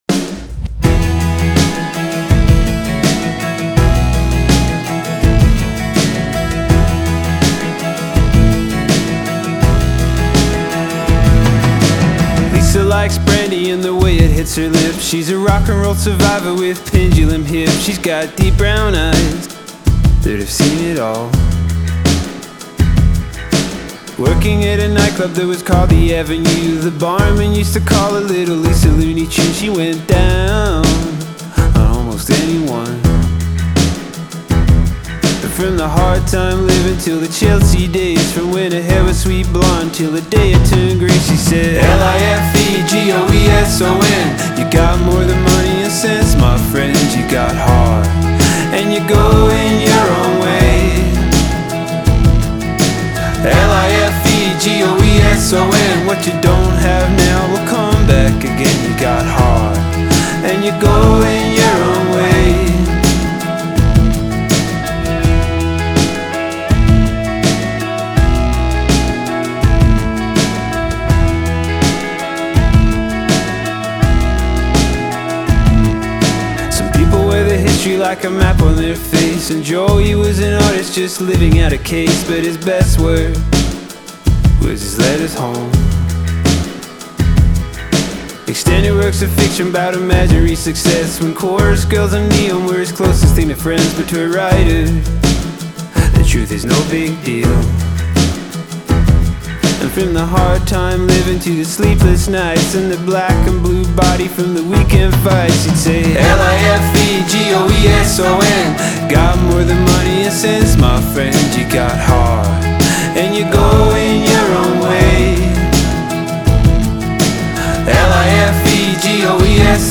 English pseudo-folk outfit